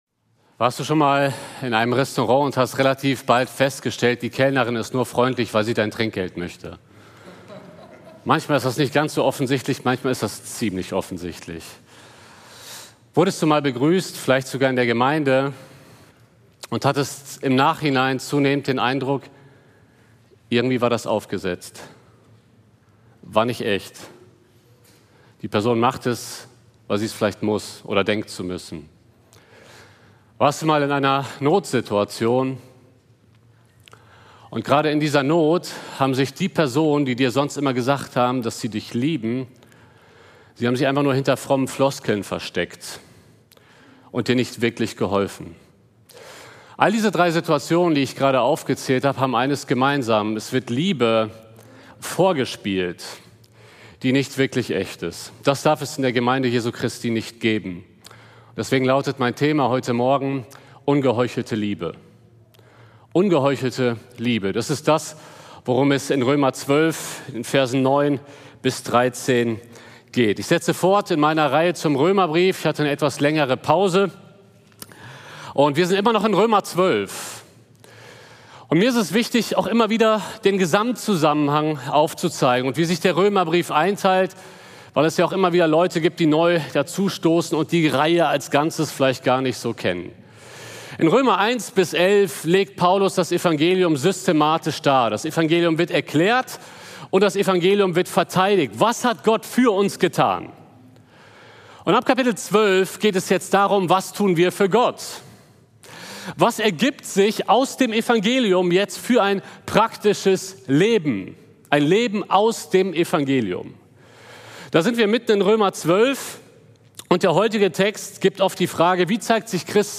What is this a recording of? Predigt-Reihe: Römerbrief